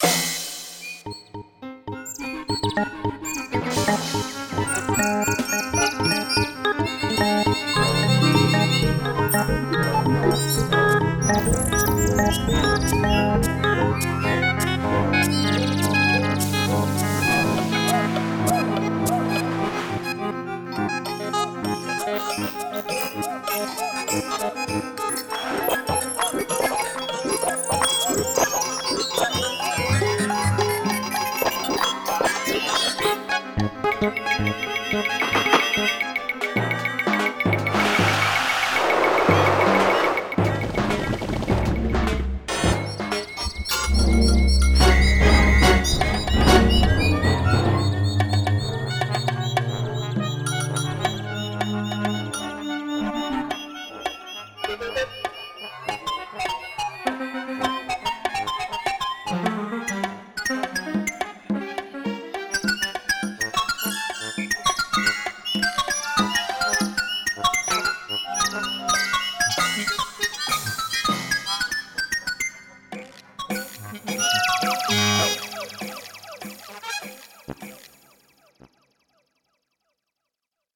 An old MIDI from 22 January 2006